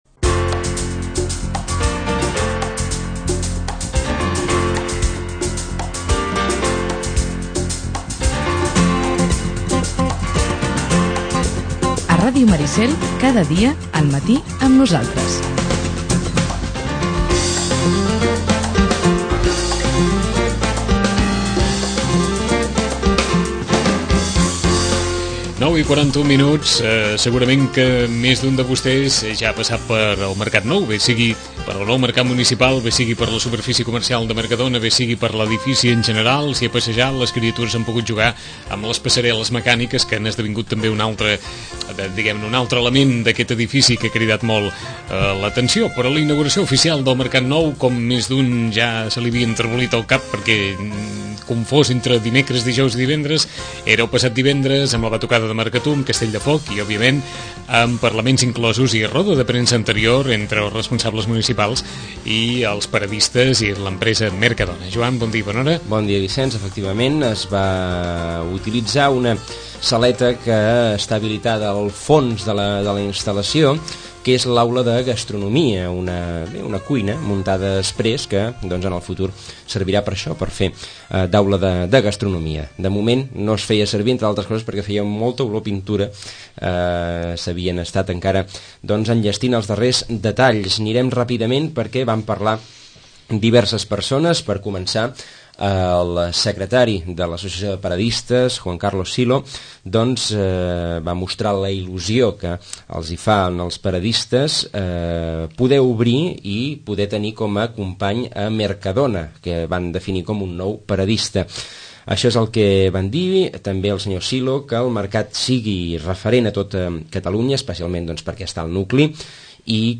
Roda de premsa prèvia a la inauguració oficial de l’edifici del Mercat Nou.